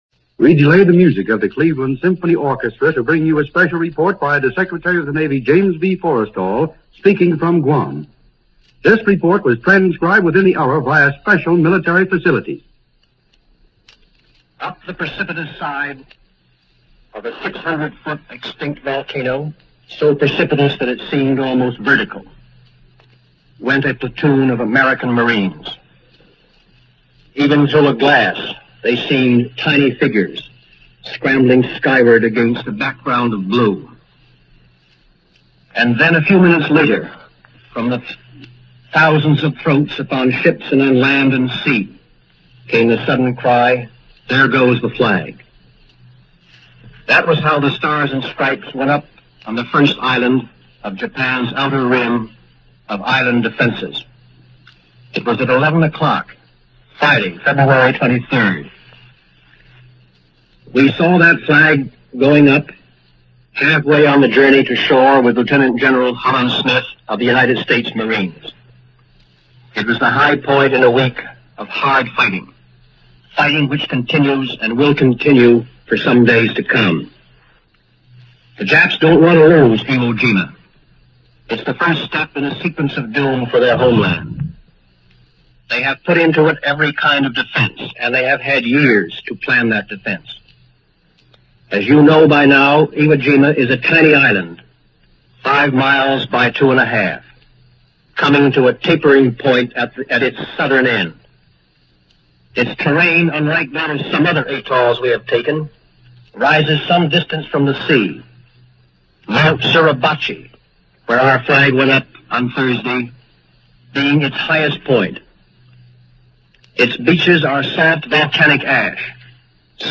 Here is the broadcast by Secretary of the Navy James Forrestal, given two days later, on February 25th – effectively boosting American morale in the process.